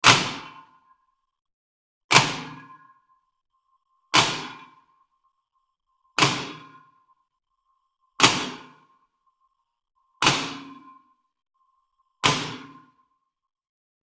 Electrical Shocks / Bursts 4; Seven Short Electrical Zaps; With Long Metallic Reverberation, Close Perspective. Arc, Spark.